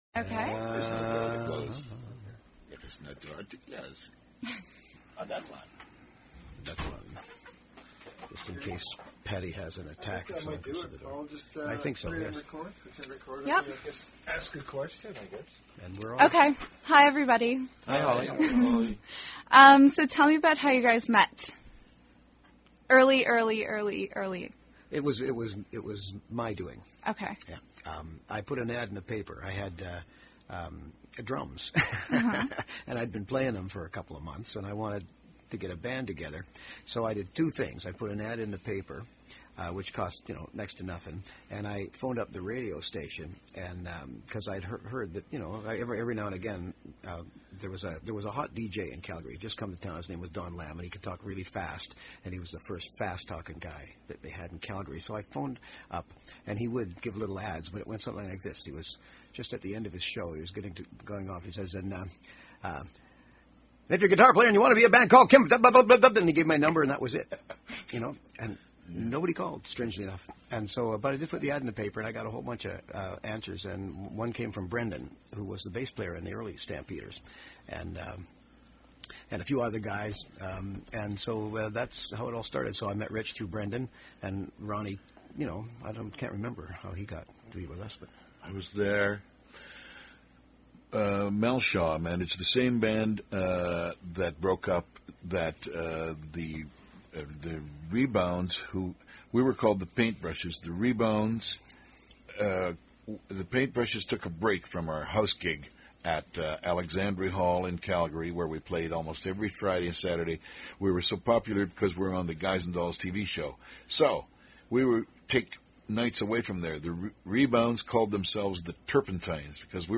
Various Stampeder interviews